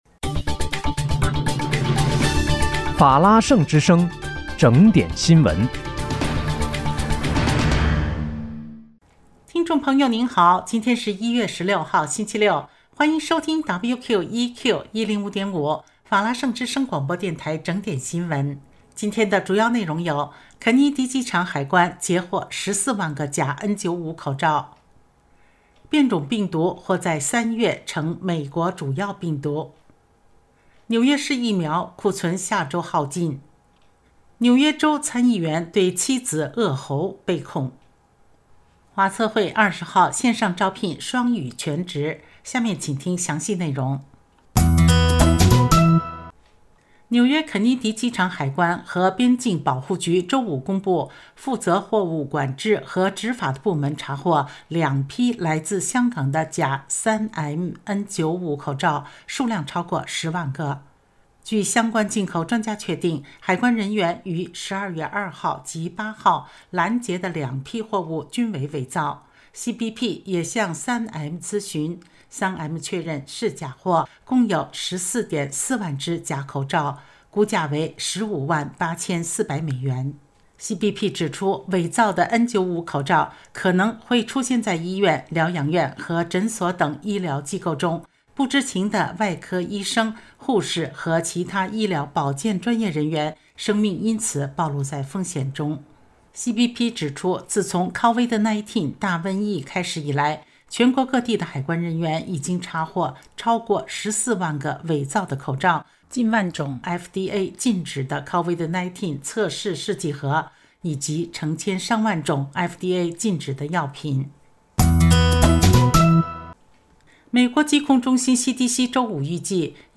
1月16日（星期六）纽约整点新闻
听众朋友好！今天是1月16号，星期六，欢迎收听WQEQ105.5法拉盛之声广播电台整点新闻。